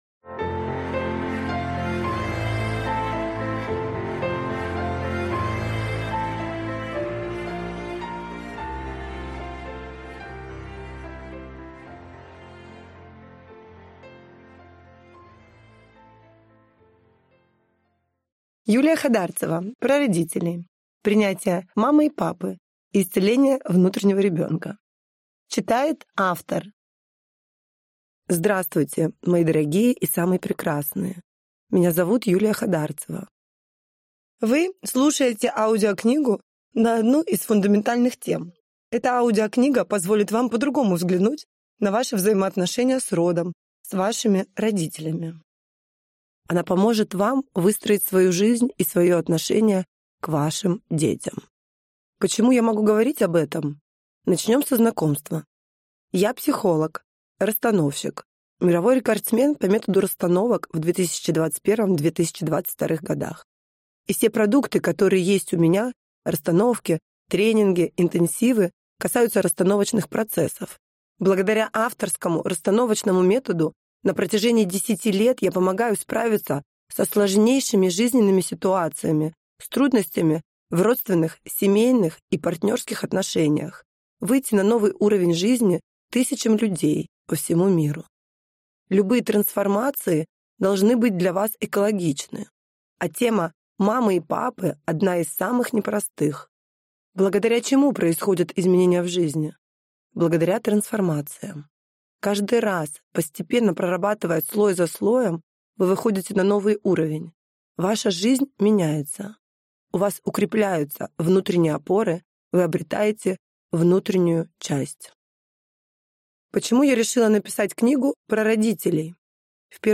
Аудиокнига Про родителей. Принятие мамы и папы и исцеление внутреннего ребенка | Библиотека аудиокниг